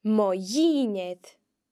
If dh is used before a slender vowel (e or i), it is pronounced similarly to the ‘y’ sound. We can hear this in the short phrase mo dhinnear (my dinner):